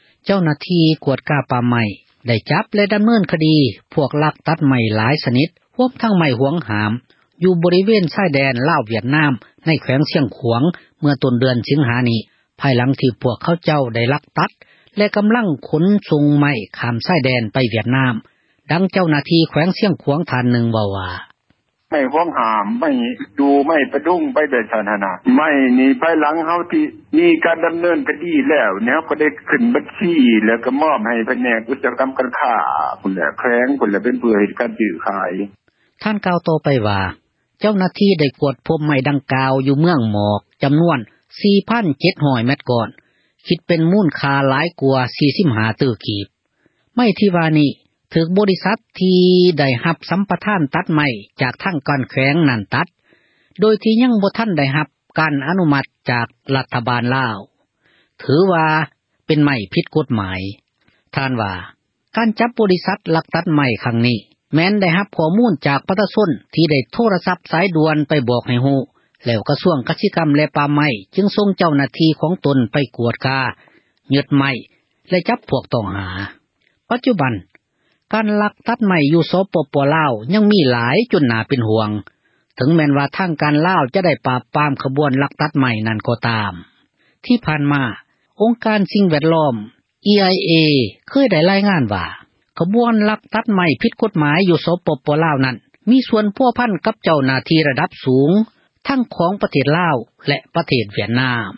ເຈົ້າໜ້າທີ່ ກວດກາ ປ່າໄມ້ ໄດ້ຈັບກຸມ ພວກ ລັກຕັດໄມ້ ທັງຍຶດໄມ້ ໄດ້ ຫຼາຍຊນິດ ຮວມທັງ ໄມ້ ຫວງຫ້າມ ຢູ່ ບໍຣິເວນ ຊາຍແດນ ລາວ-ວຽດນາມ ໃນ ແຂວງ ຊຽງຂວາງ ເມື່ອ ຕົ້ນເດືອນ ສິງຫາ ນີ້ ພາຍຫລັງທີ່ ພວກເຂົາເຈົ້າ ໄດ້ລັກຕັດ ແລະ ກໍາລັງ ຂົນໄມ້ ຂ້າມ ຊາຍແດນ ໄປ ວຽດນາມ. ດັ່ງ ເຈົ້າໜ້າທີ່ ແຂວງ ຊຽງຂວາງ ເວົ້າວ່າ: